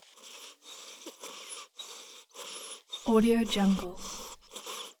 دانلود افکت صدای نفس کشیدن گربه
Sample rate 16-Bit Stereo, 44.1 kHz
Looped No